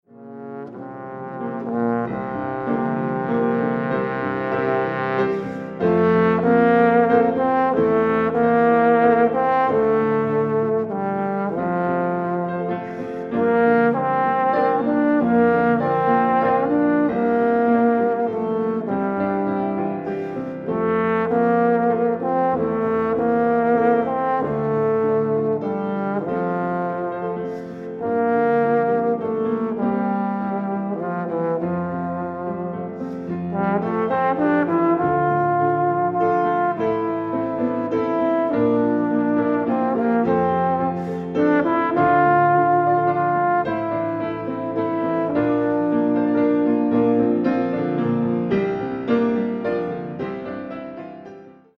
Trombone
Piano This album features myself on trombone and my father